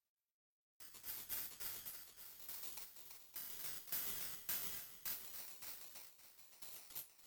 心霊系ノイズ効果音
フリー音源　心霊系ノイズ音